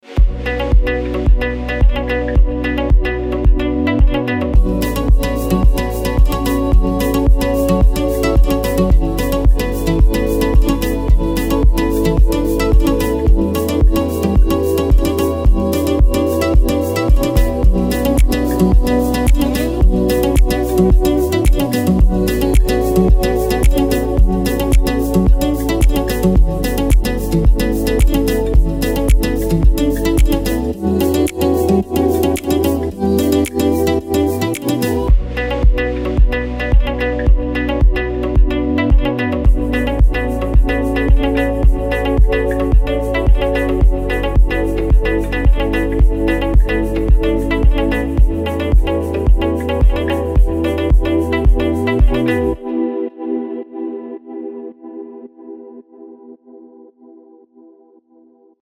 спокойные
без слов
Downtempo
инструментальные
нежные